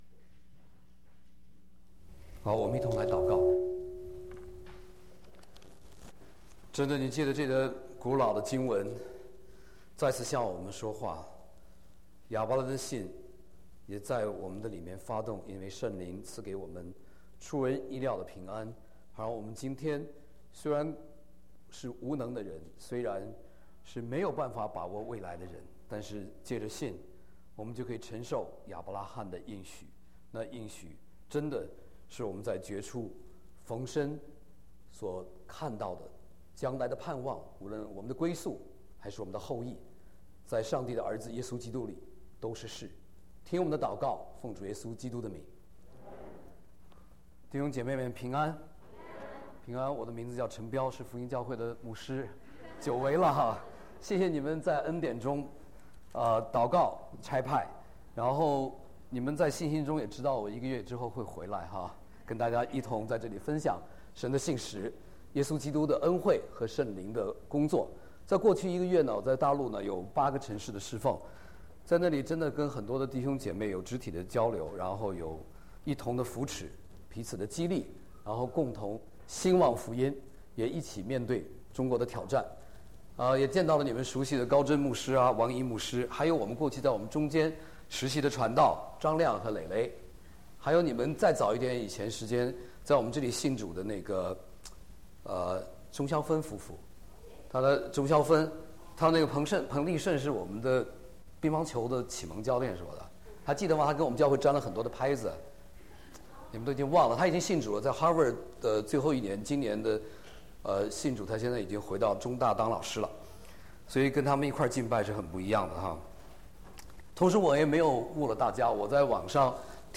Orlando Chinese Evangelical Christian Church 奥兰多华人福音教会